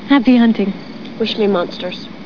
Here you will find various sounds taken from Buffy, the Vampire Slayer.